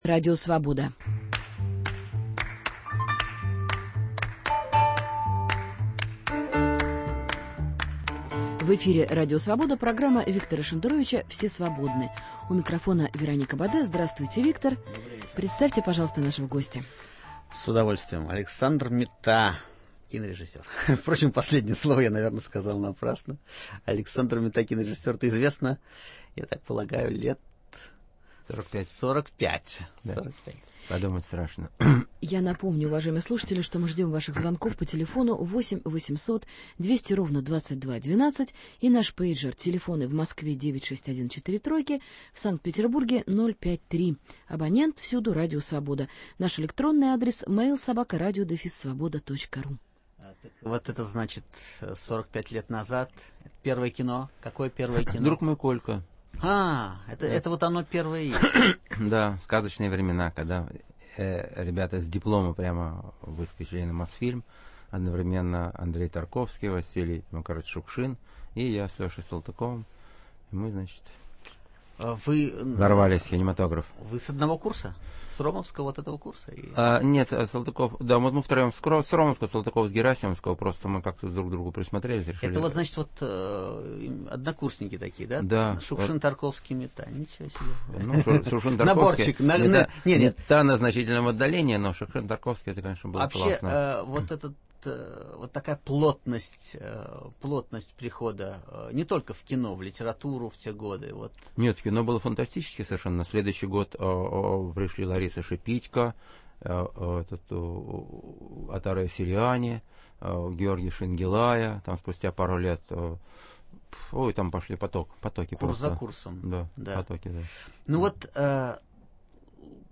В гостях у Виктора Шендеровича кинорежиссер Александр Митта